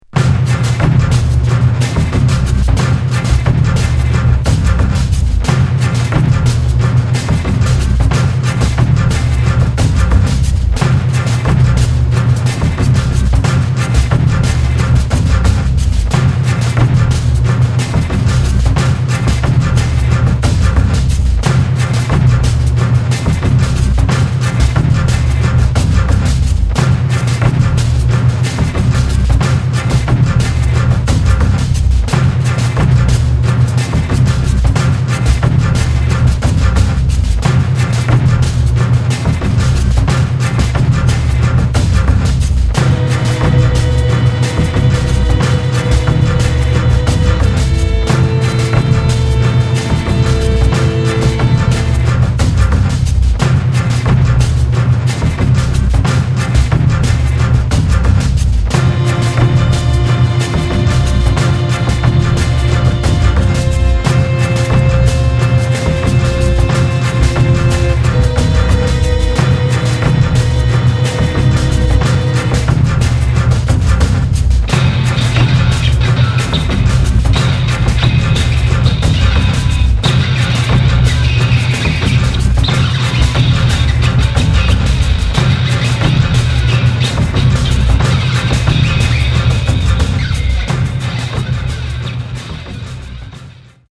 ミニマル　室内楽　空想民俗